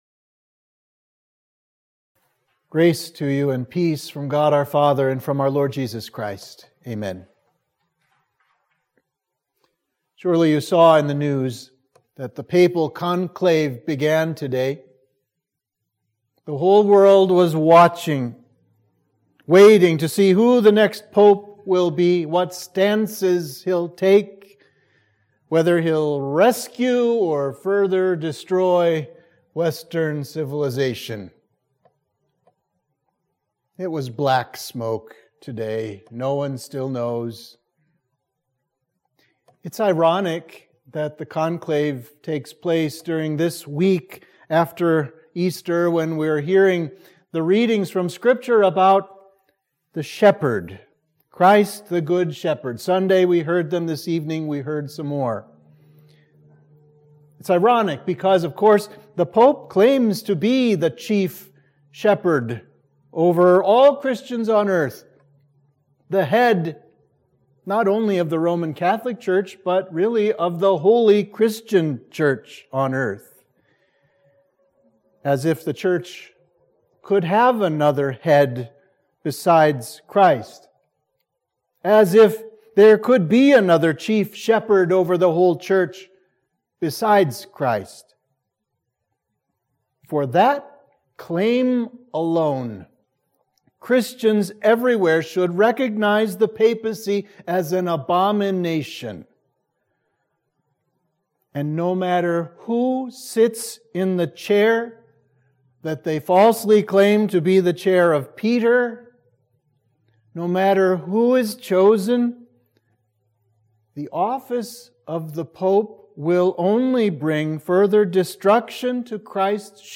Sermon for Midweek of Easter 2